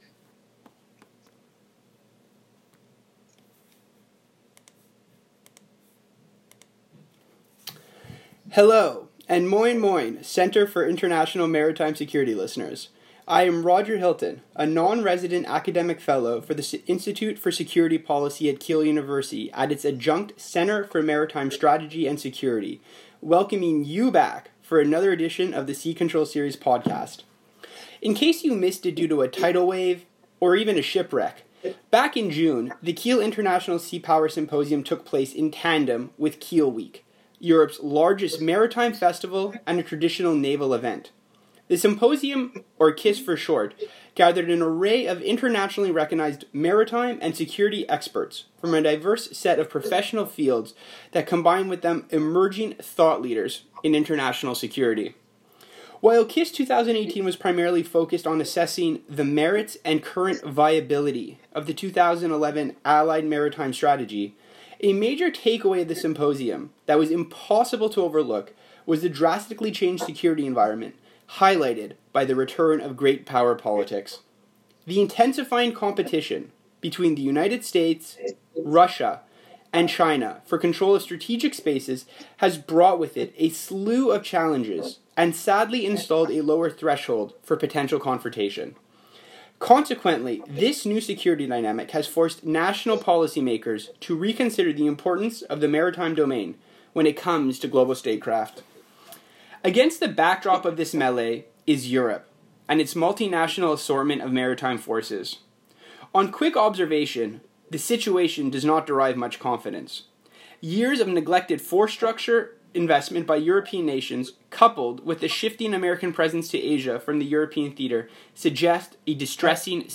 Click here for the interveiw transcription on CIMSEC.